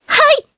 Laias-Vox_Attack2_jp.wav